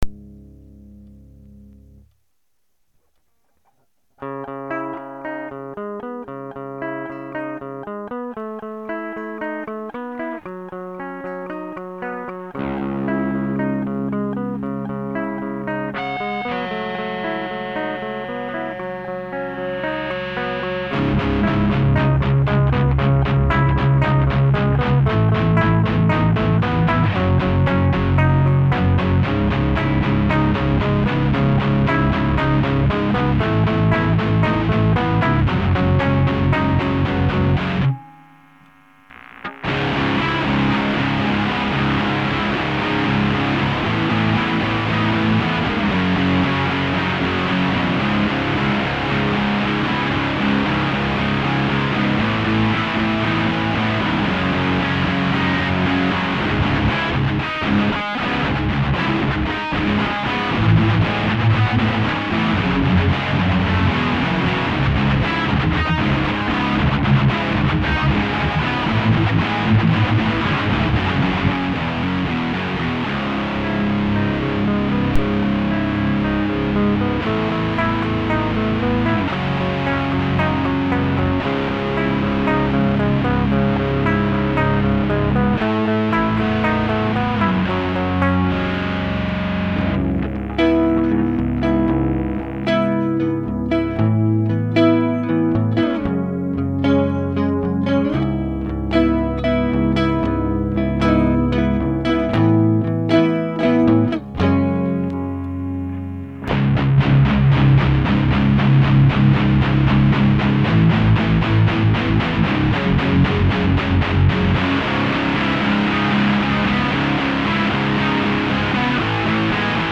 3D Spatial Sounds